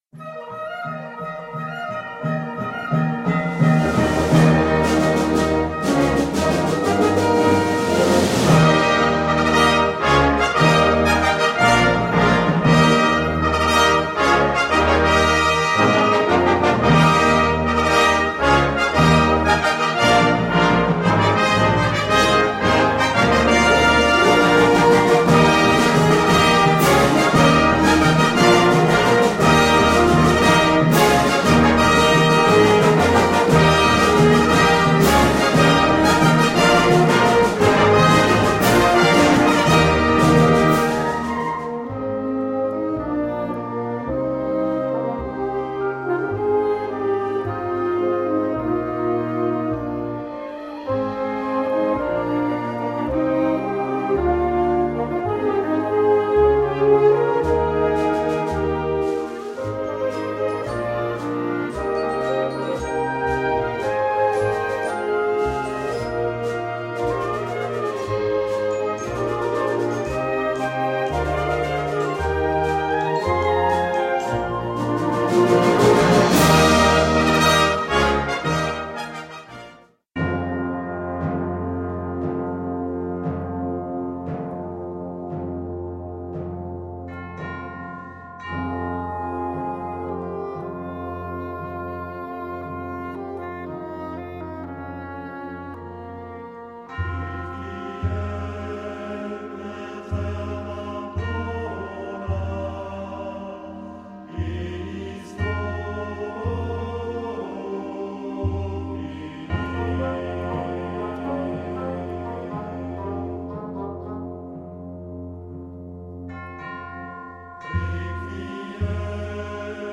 Catégorie Harmonie/Fanfare/Brass-band
Cette 4e partie débute avec l'horloge de la Tour à l'aurore.